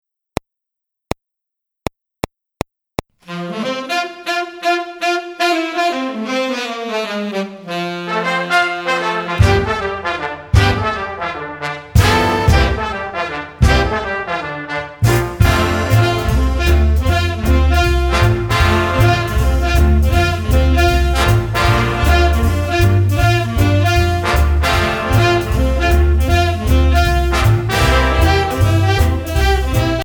Altsaxophon in Es